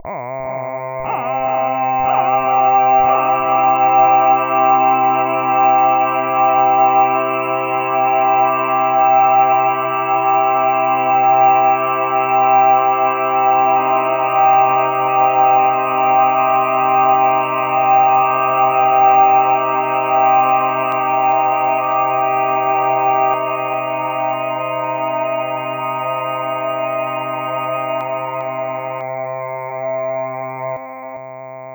syntetiserade körklang
korsyntes_original.wav